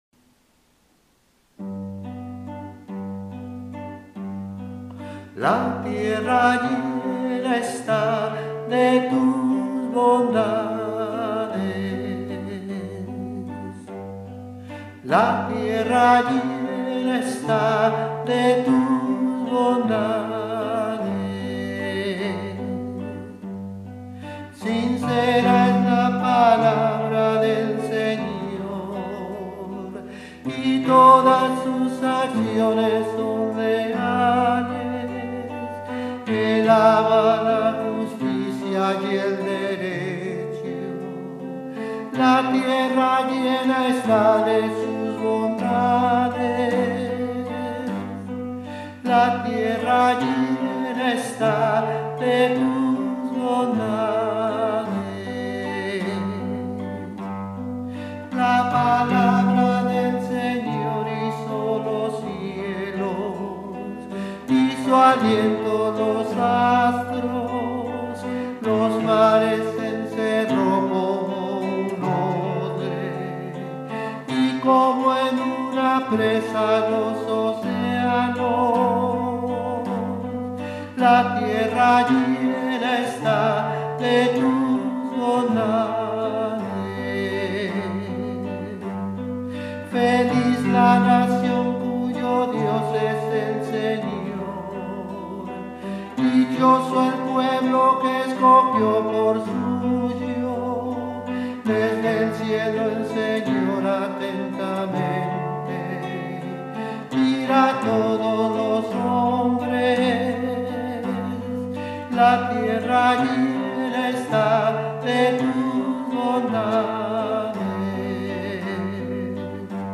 SALMO RESPONSORIAL Del salmo 32 R. La tierra llena está de tus bondades.
salmo-32-Vigilia-Pascual-noche.mp3